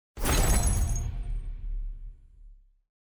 ui_interface_27.wav